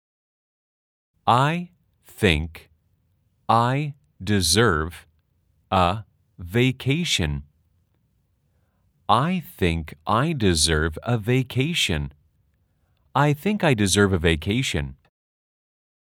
아주 천천히-천천히-빠르게 3번 반복됩니다.
/ 아이 띠잉크 아이 디저어버 / 베캐이션 /